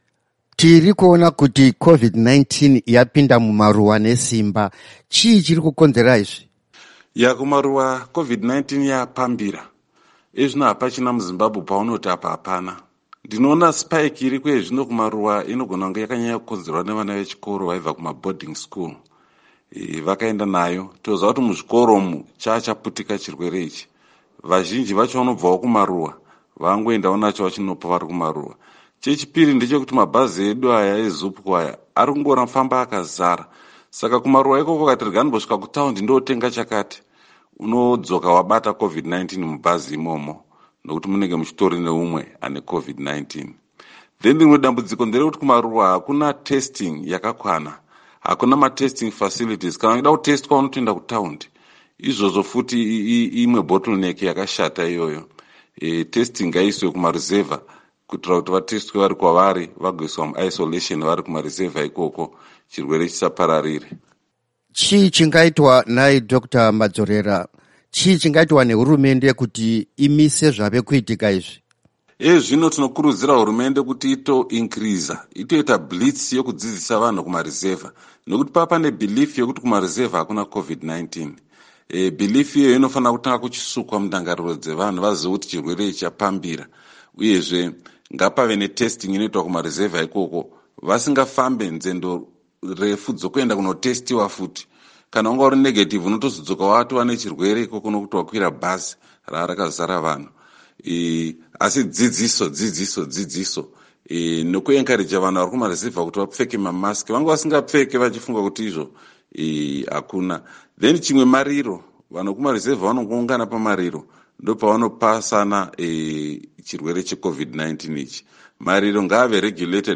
Hurukuro naDr Henry Madzorera